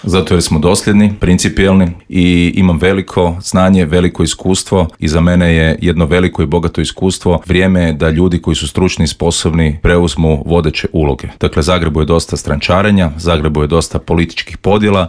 Zagreb već zna nekoliko kandidata za gradonačelnika, a svoju kandidaturu objavio je i nezavisni kandidat Davor Bernardić koji je u Intervjuu Media servisa poručio: "Imamo rješenje za prometne probleme, a Tomaševićevi najavljeni projekti su samo prazno predizborno obećanje. Nema šanse da Maksimir bude gotov do 2028. kao ni Centar za gospodarenje otpadom."